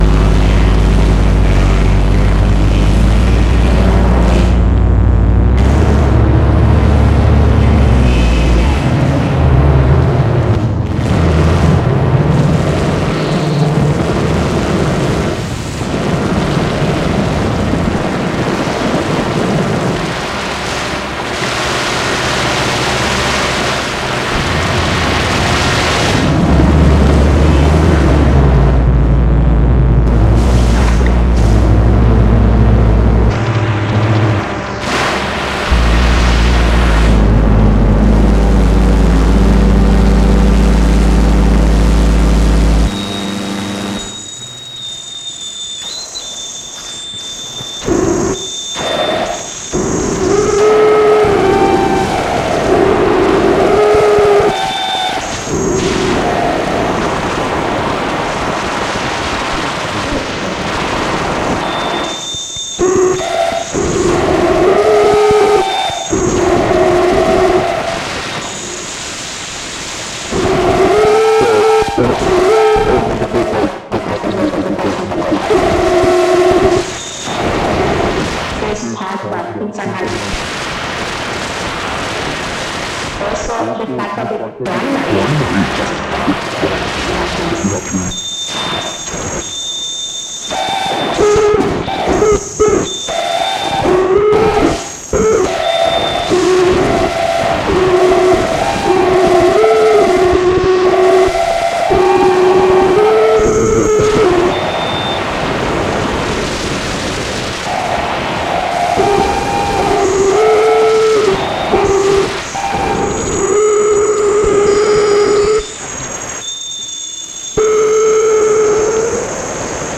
noise, harsh noise,